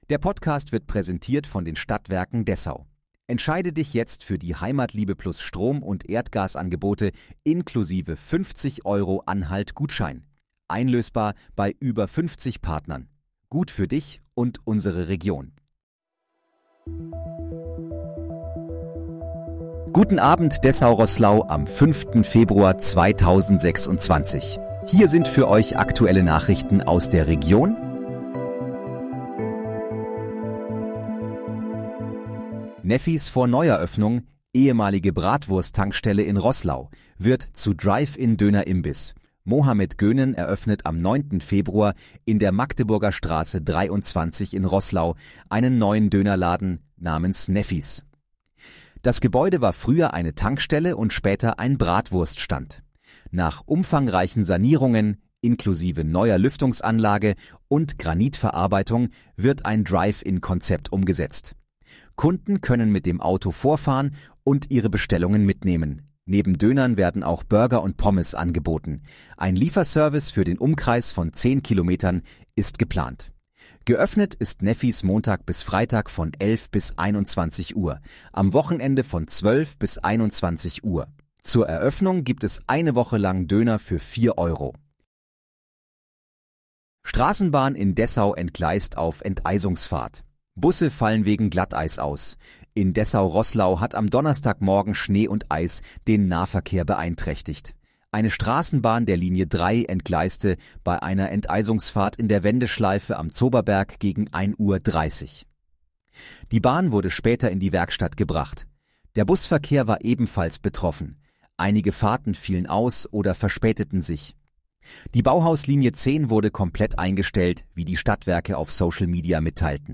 Guten Abend, Dessau Rosslau: Aktuelle Nachrichten vom 05.02.2026, erstellt mit KI-Unterstützung
Nachrichten